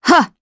Valance-Vox_Attack6_jp.wav